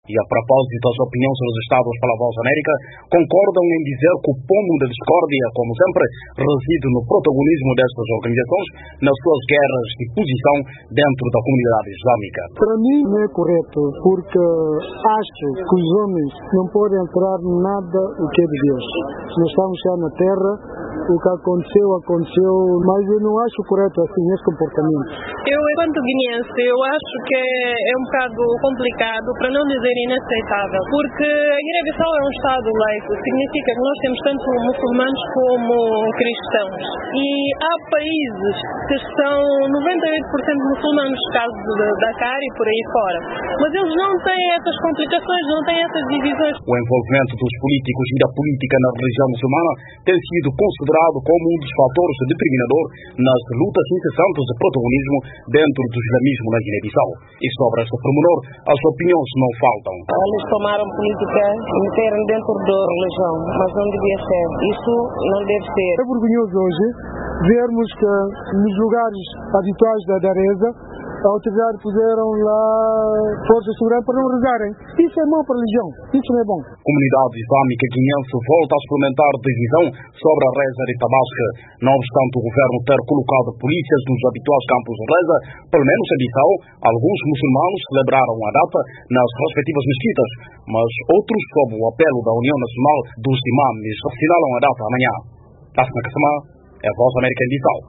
Vozes registadas pela VOA concordam que o pomo da discórdia, como sempre, reside no protagonismo destas organizações nas suas guerras de posição dentro da comunidade islâmica.